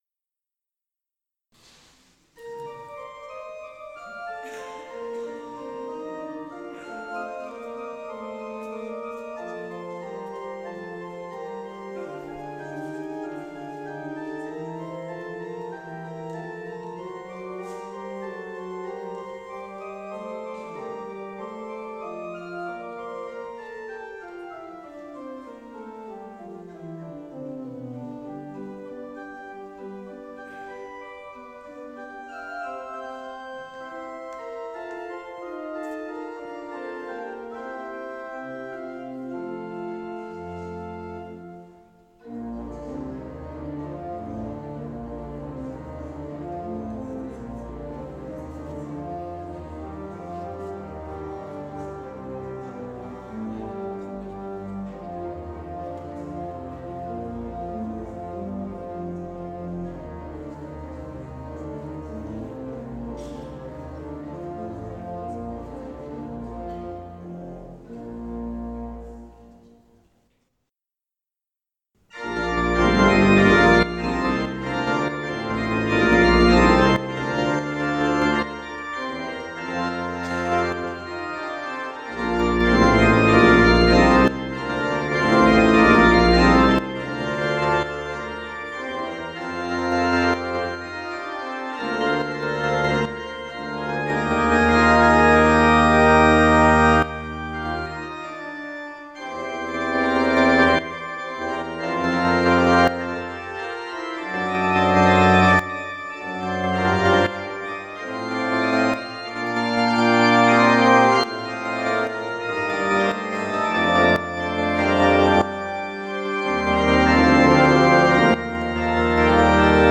Aktuelle Predigt